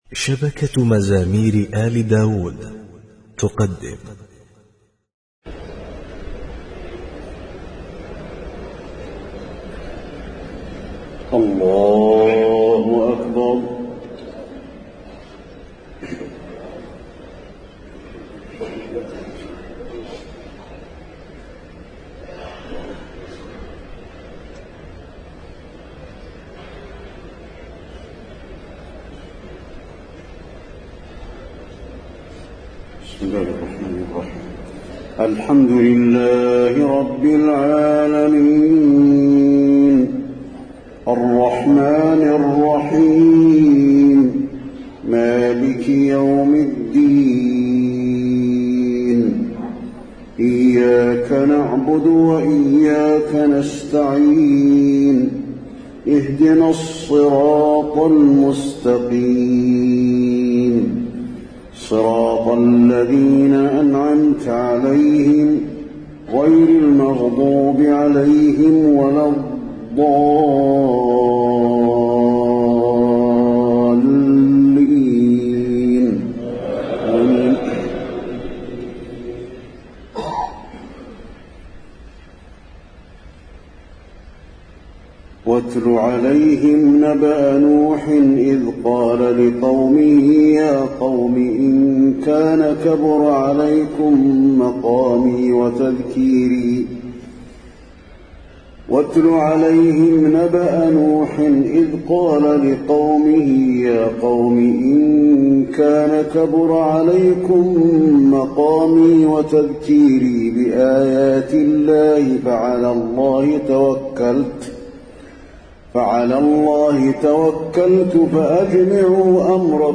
تهجد ليلة 29 رمضان 1432هـ من سورتي يونس (71-109) وهود (1-49) Tahajjud 29 st night Ramadan 1432H from Surah Yunus and Hud > تراويح الحرم النبوي عام 1432 🕌 > التراويح - تلاوات الحرمين